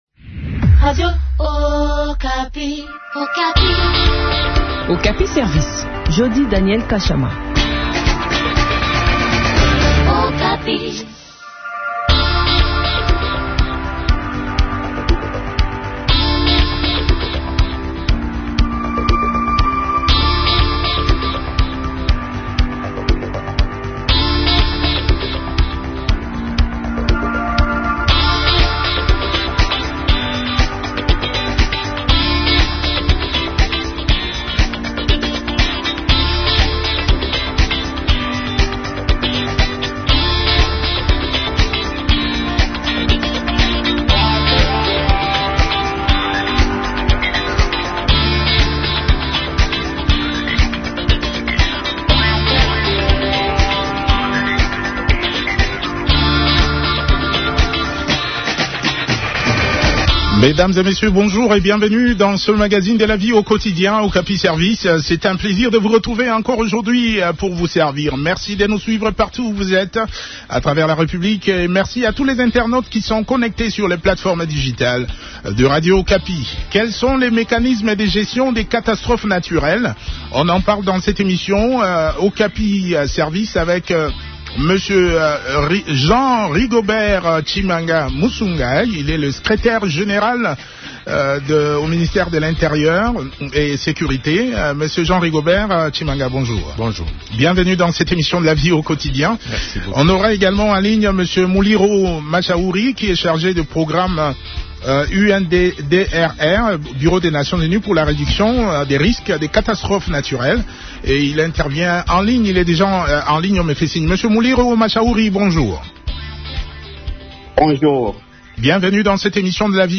Lors d’un entretien